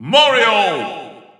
The announcer saying Mario's name in English and Japanese releases of Super Smash Bros. 4 and Super Smash Bros. Ultimate.
Category:Mario (SSB4) Category:Mario (SSBU) Category:Announcer calls (SSB4) Category:Announcer calls (SSBU) You cannot overwrite this file.
Mario_English_Announcer_SSB4-SSBU.wav